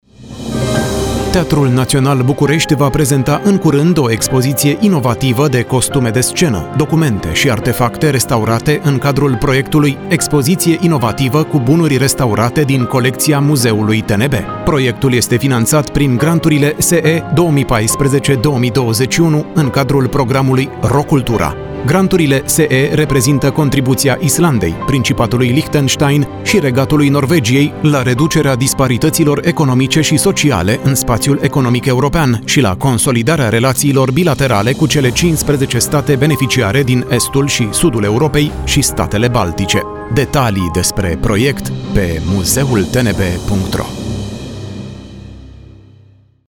Spoturi promoționale difuzate la RFI Romania.